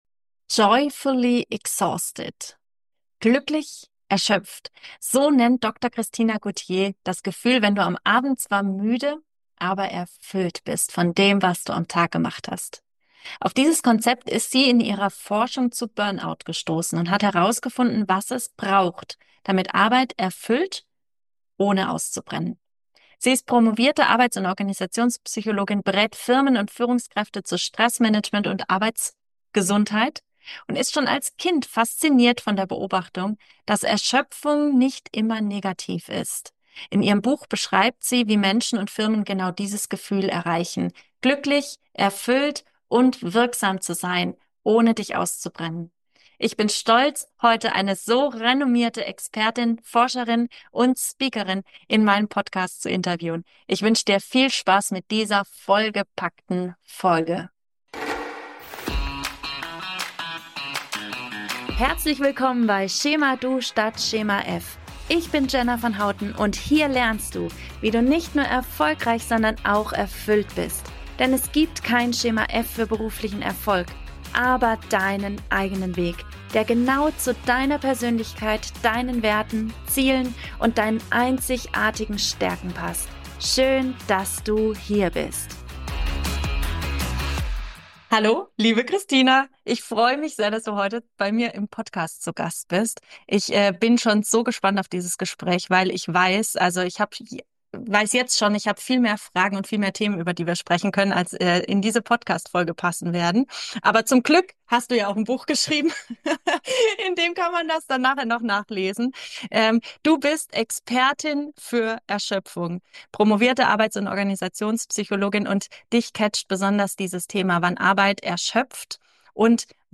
'Joyfully exhausted' Was Menschen brauchen, um nicht auszubrennen | Im Interview mit